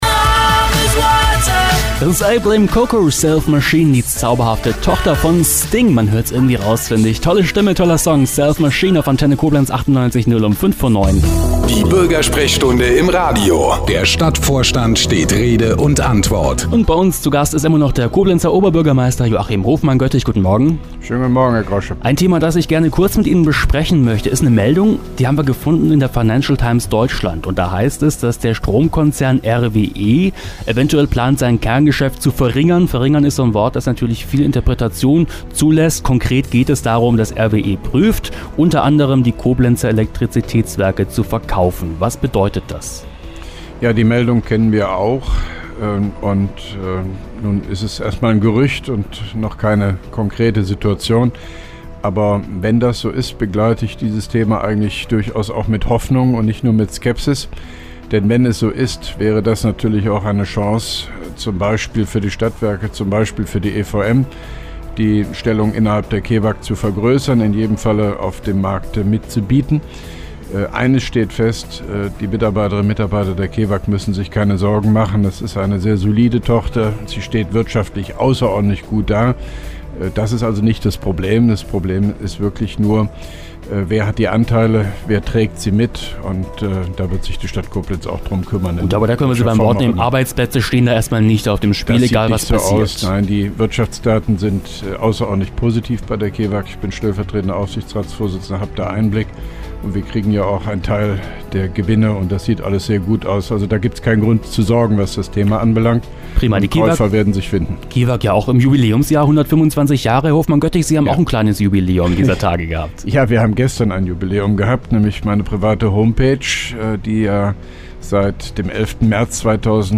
(3) Koblenzer Radio-Bürgersprechstunde mit OB Hofmann-Göttig 02.08.2011